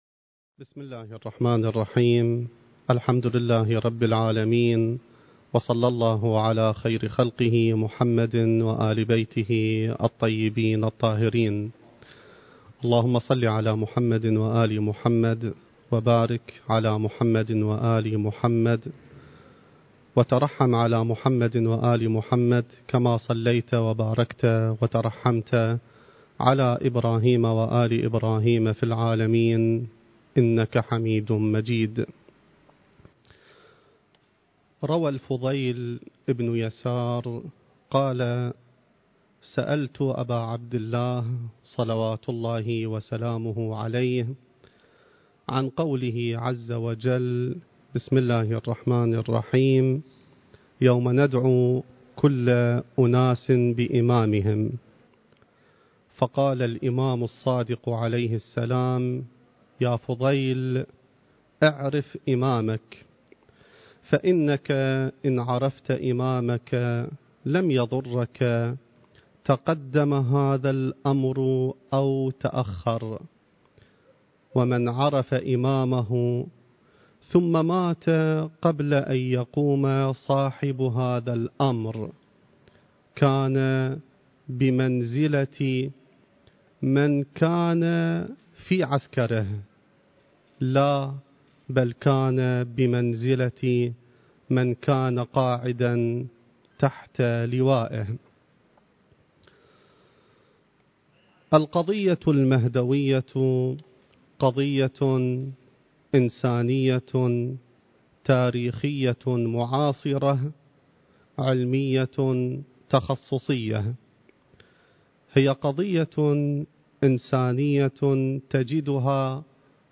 المكان: العتبة العلوية المقدسة الزمان: ذكرى ولادة الإمام المهدي (عجّل الله فرجه) التاريخ: 2020